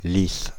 Lisses (French pronunciation: [lis]
Fr-Paris--Lisses.ogg.mp3